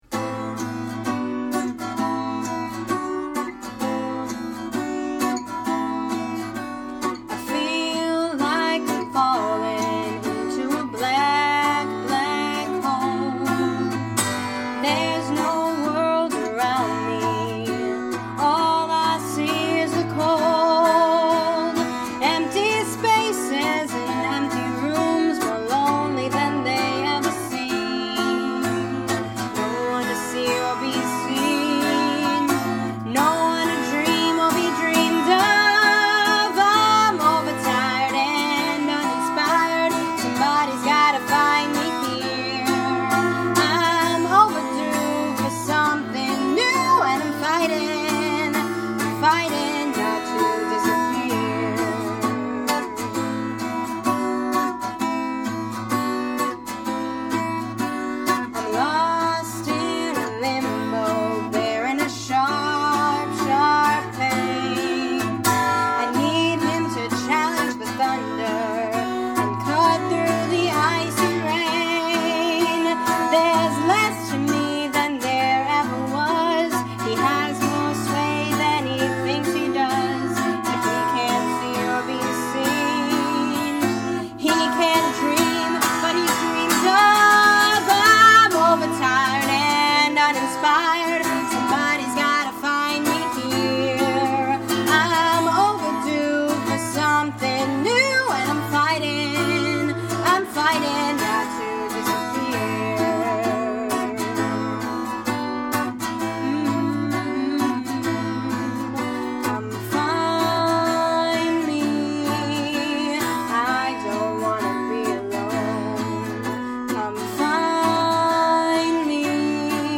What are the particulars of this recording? I was visiting my brother and was lucky enough to get his roommate to play guitar for me and we did a quick demo recording of it.